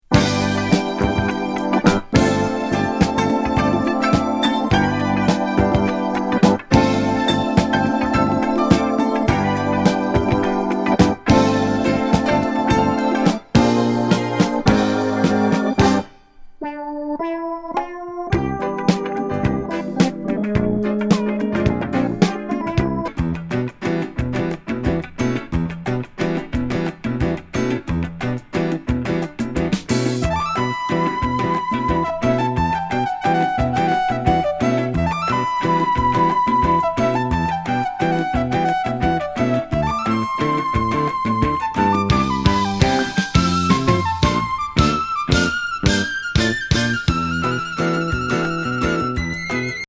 女性キーボード奏者作品。エレクトーン・スムース・フュージョン